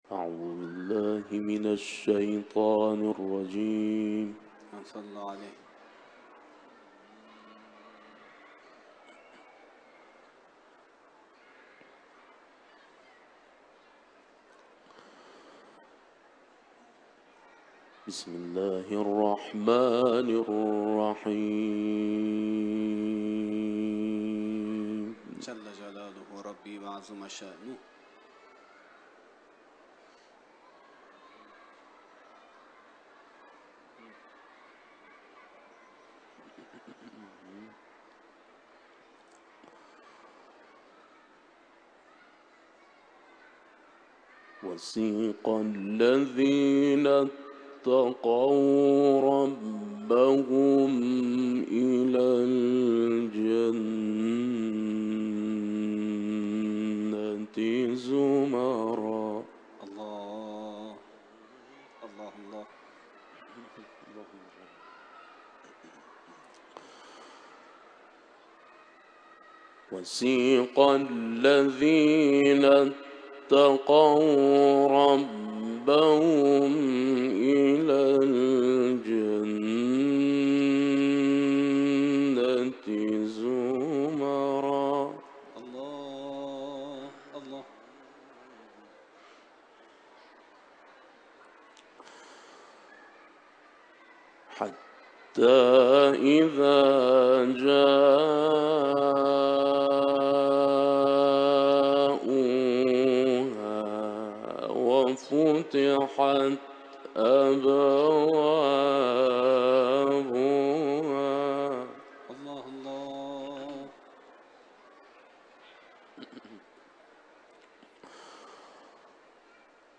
په زړه پورې تلاؤت
په رضوی مقدس حرم کې اجرا شوی دی